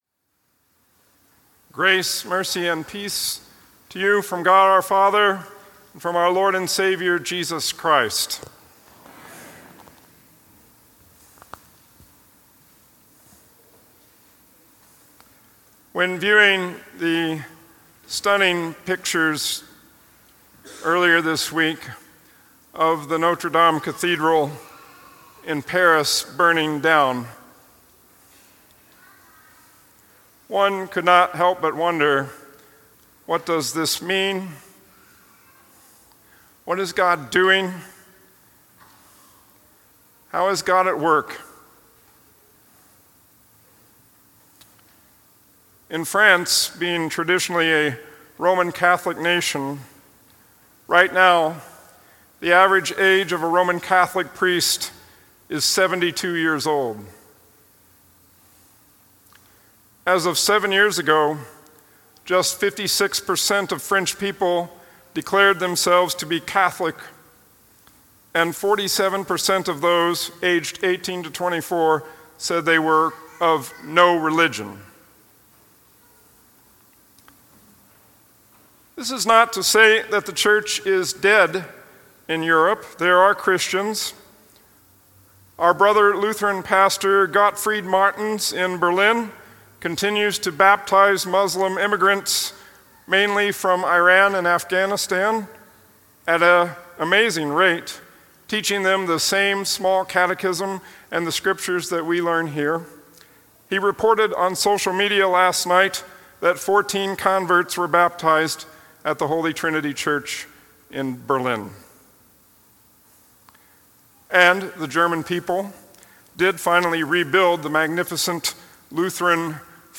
Resurrection of Our Lord – Chief Service